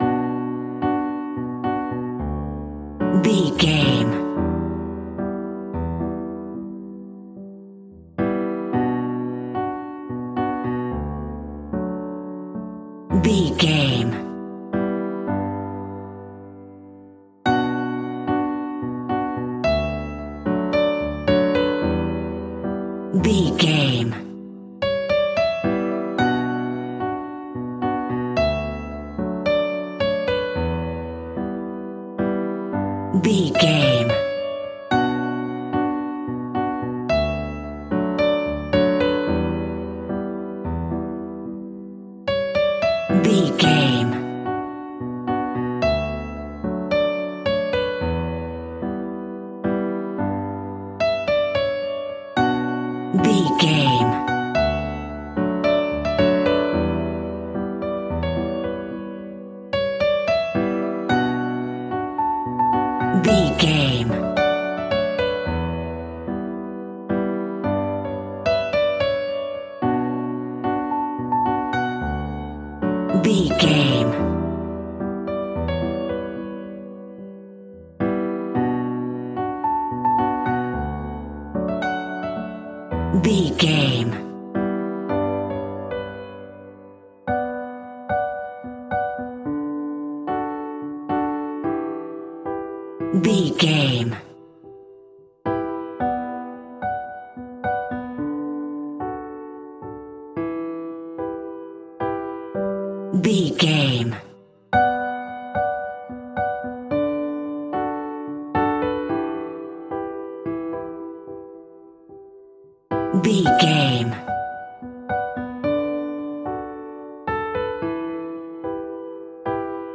Ionian/Major
Slow
light
relaxed
tranquil
synthesiser
drum machine